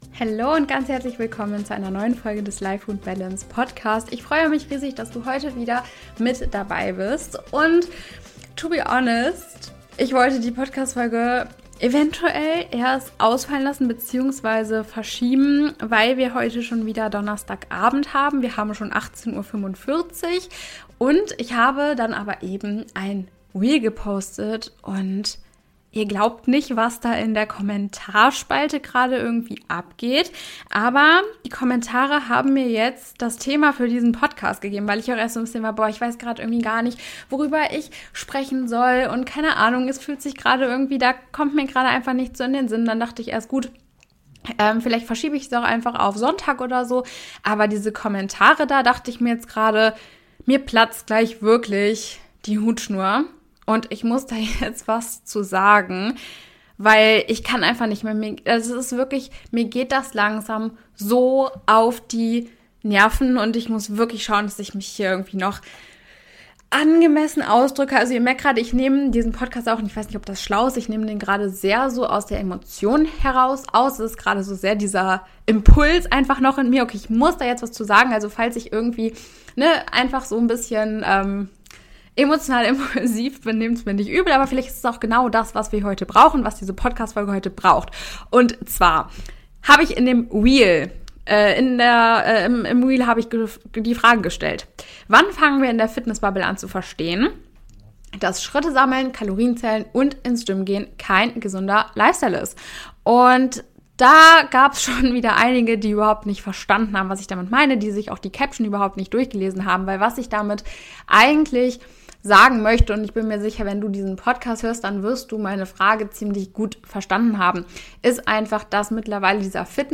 Doch ein gesunder Lifestyle ist das nicht automatisch. Mehr dazu erfährst du in der Folge - achtung, eventuell impulsiv und emotional!